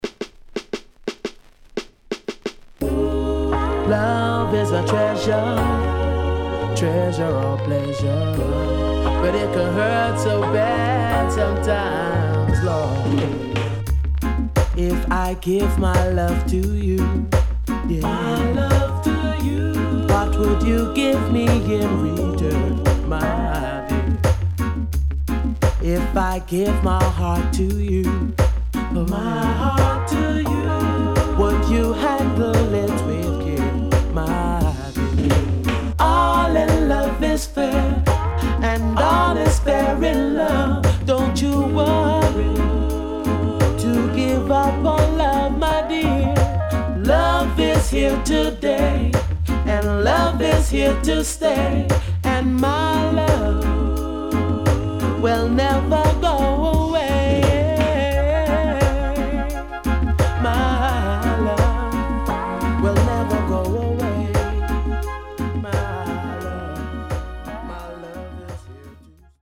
【12inch】
SIDE A:少しチリノイズ入りますが良好です。